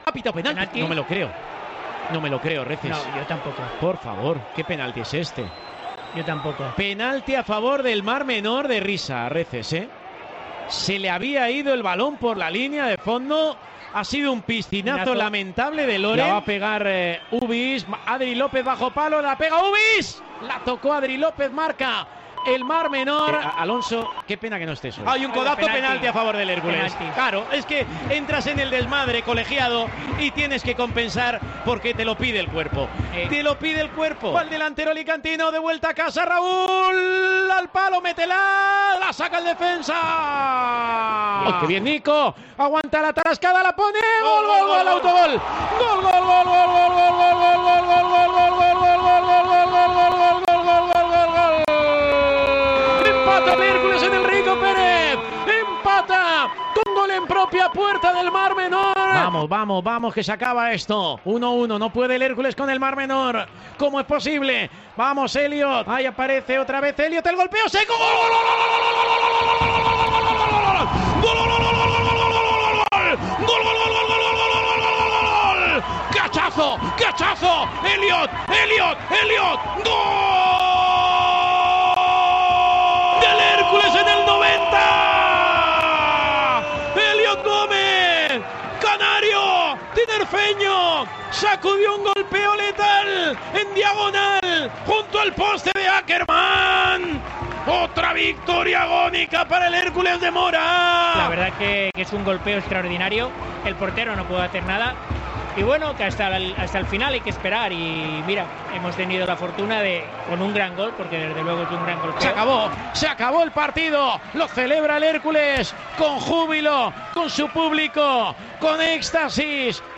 Así sonó en el Tiempo de Juego de COPE otro triunfo en el último minuto del Hércules